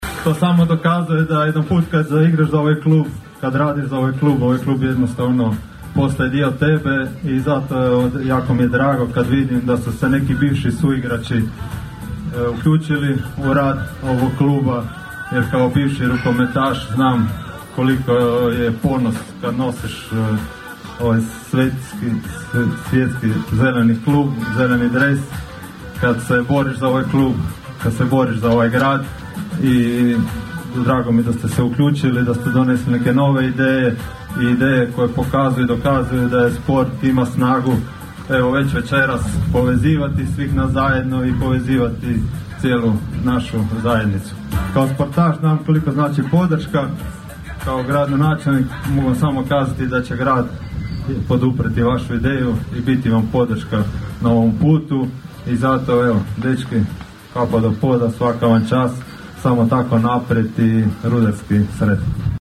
Pod nazivom "Season Kick-off 2025./26.", Rukometni klub Rudar jučer je u prelijepom ambijentu Glamping campa Floria predstavio viziju, ciljeve, momčad, stručni stožer i novi Izvršni odbor za nadolazeću sezonu.
Okupljenima su se obratili i gradonačelnik Labina Donald Blašković te njegov zamjenik Goran Vlačić, obojica nekadašnji rukometaši.